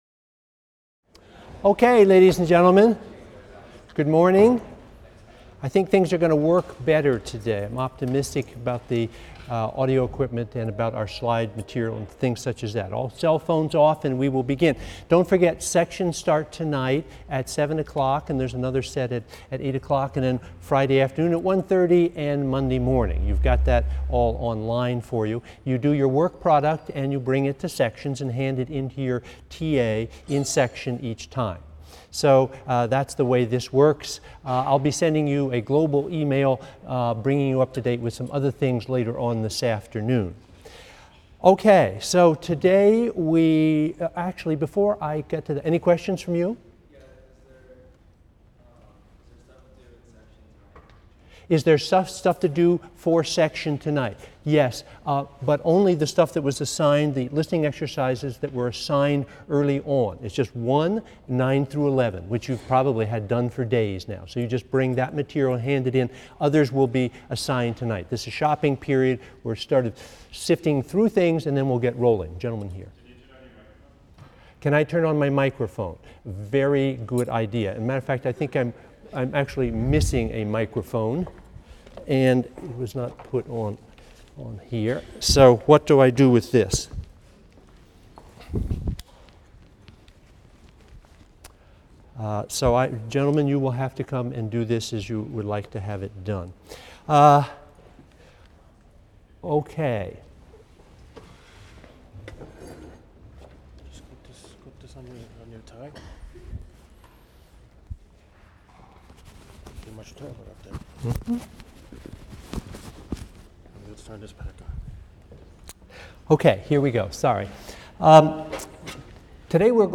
MUSI 112 - Lecture 3 - Rhythm: Fundamentals | Open Yale Courses